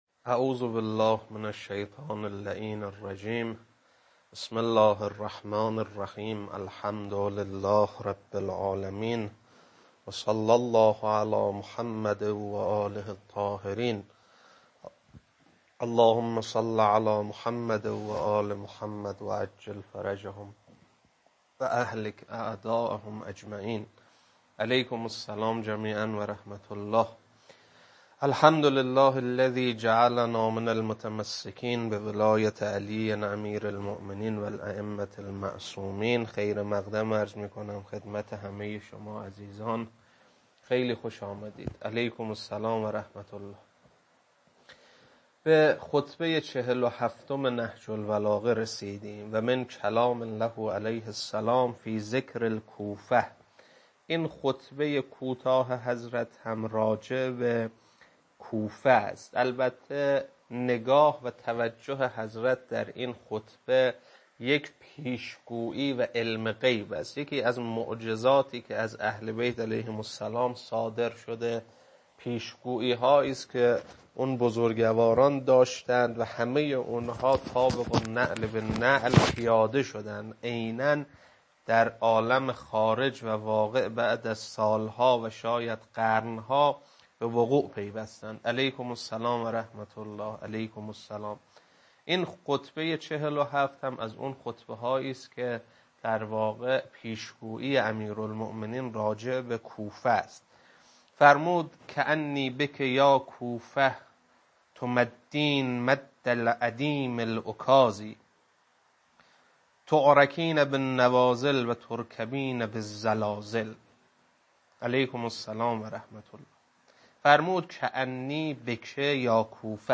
خطبه 47.mp3